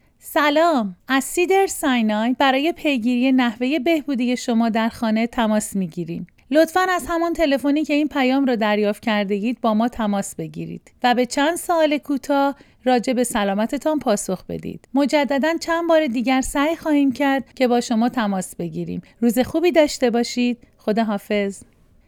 Female
IVR